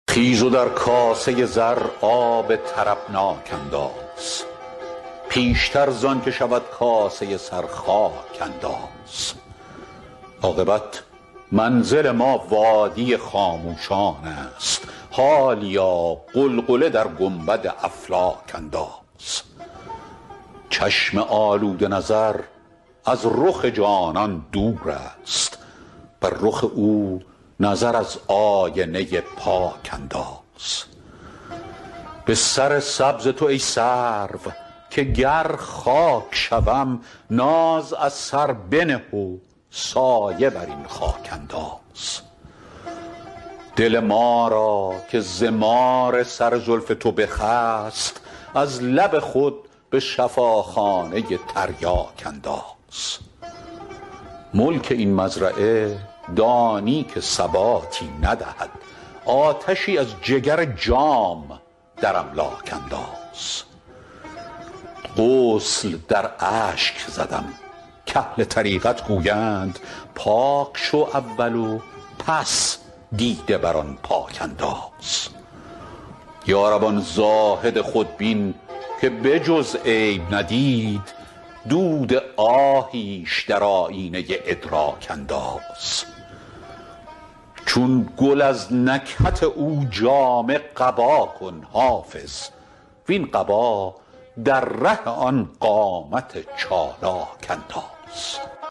حافظ غزلیات غزل شمارهٔ ۲۶۴ به خوانش فریدون فرح‌اندوز